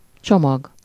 Ääntäminen
IPA: [ba.ɡaʒ]